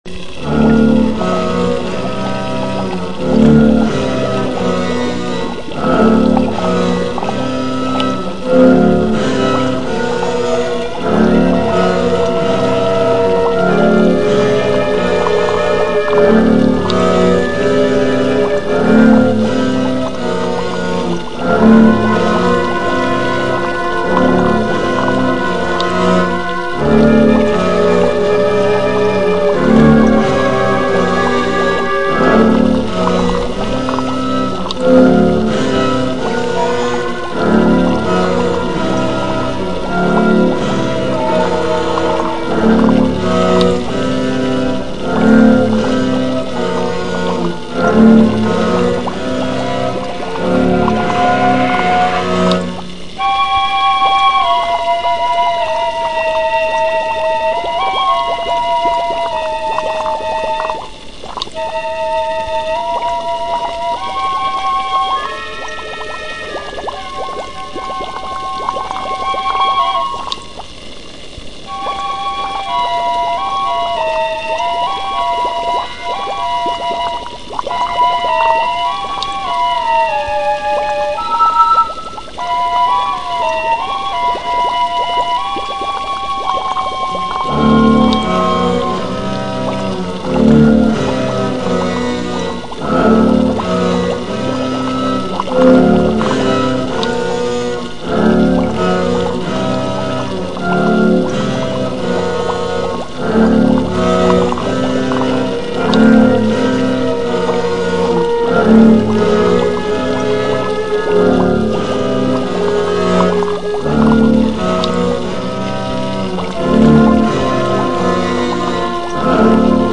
Instruments: Wooden flute, boiling water, cello.